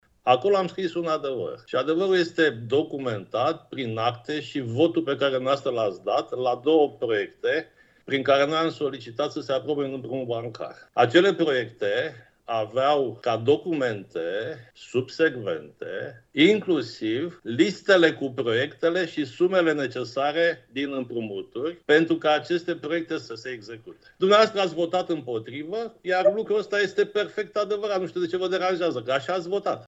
Ședința Consiliului Local Constanța a început astăzi cu discuții aprinse între consilierul local USR Florin Cocargeanu și primarul Vergil Chițac.
Primarul Vergil Chițac: